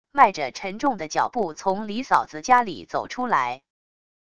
迈着沉重的脚步从梨嫂子家里走出来wav音频生成系统WAV Audio Player